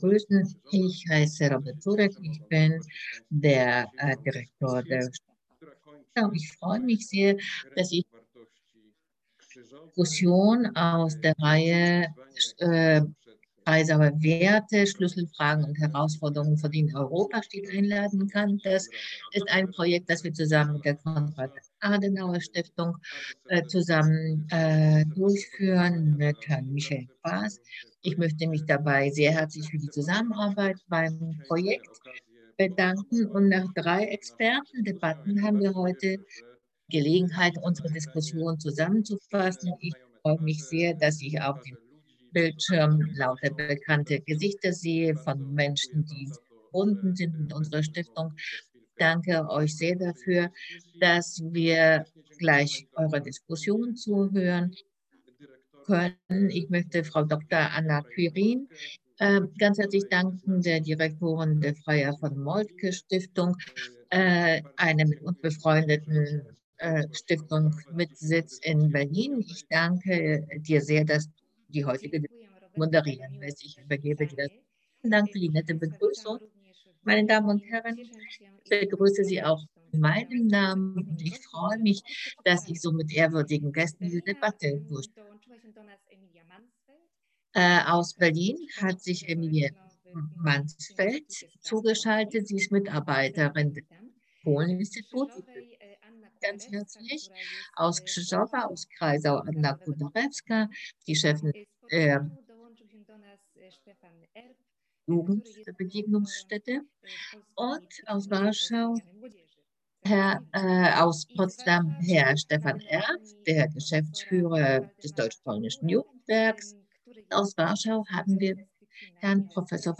Die Debatte war simultan gedolmetscht. Die Aufzeichnung der Debatte - Deutsch: Die Aufzeichnung der Debatte - Polnisch: Die Veranstaltung fand auf der ZOOM-Plattform statt und wurde live auf Facebook übertragen.